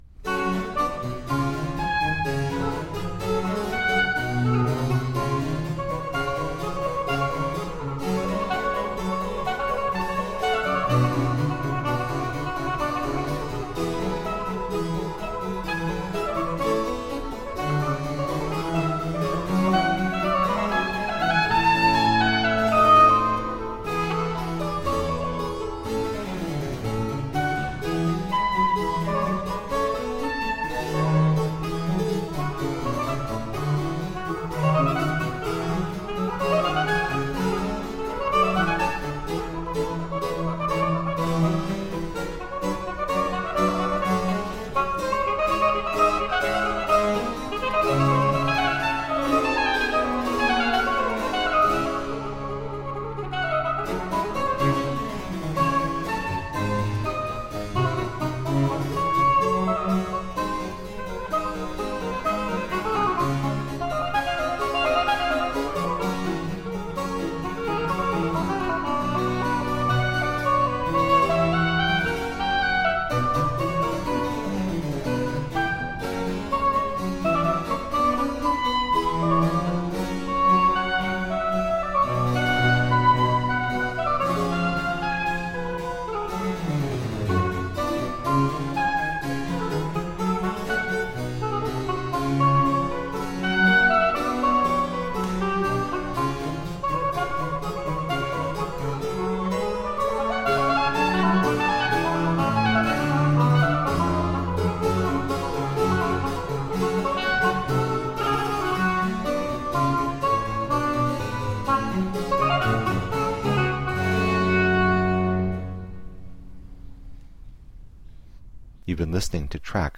Baroque oboist extraordinaire.
Classical, Chamber Music, Baroque, Instrumental, Cello
Harpsichord, Oboe